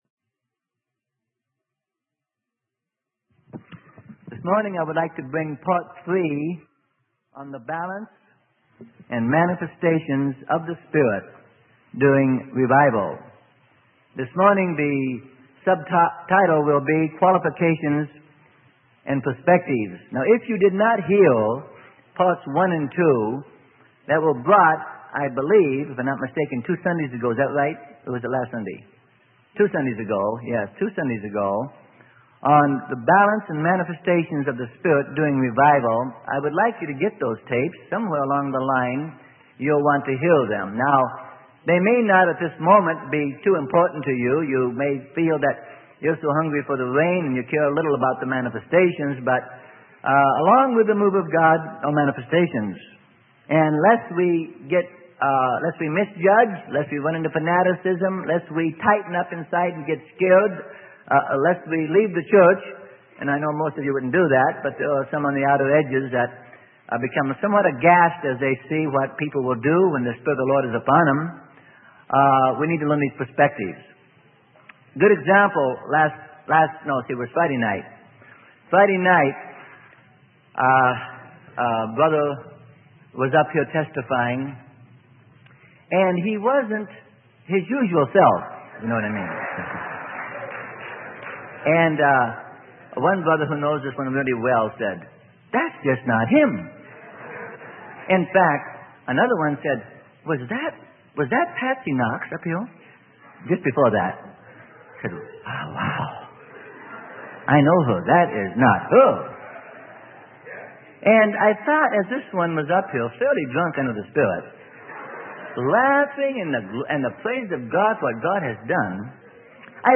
Sermon: The Balance in Manifestations of the Spirit - Part 3 - Freely Given Online Library